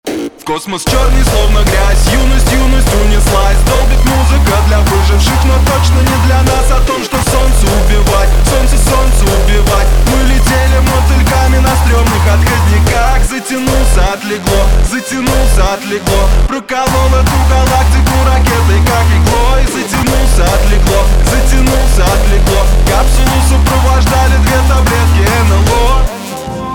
• Качество: 192, Stereo
мужской голос
громкие
мощные
русский рэп